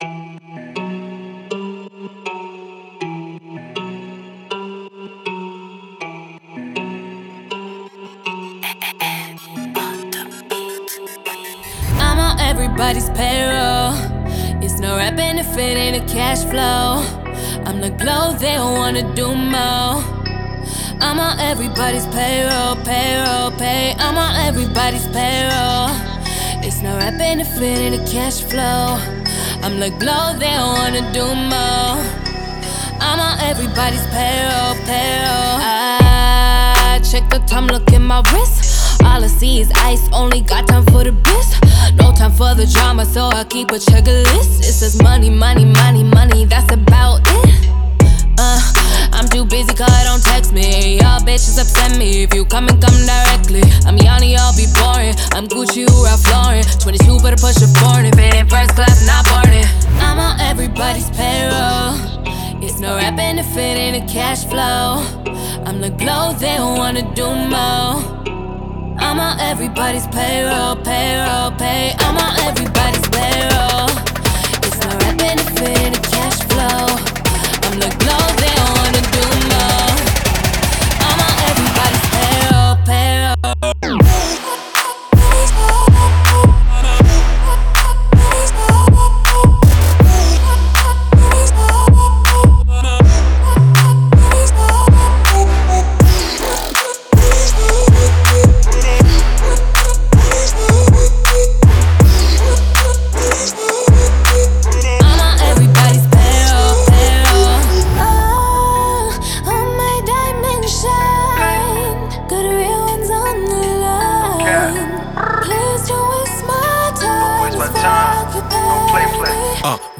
это энергичная композиция в жанре хип-хоп